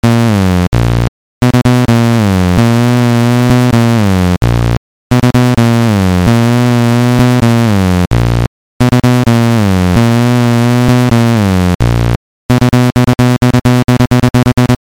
Сегодня запустил Сонар, загрузил проект, включил воспроизведение и обнаружил фоновый, так сказать, треск и скрежет регулярного характера.